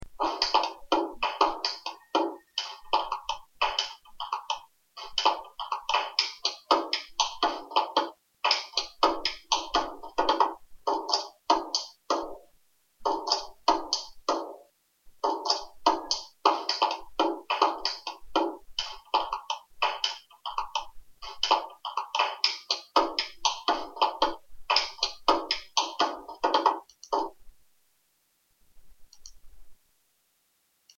És una bona forma de treballar el ritme i la percussió corporal, i alhora l’alumne se sent partícip d’aquest projecte, com a creador i després com a executant.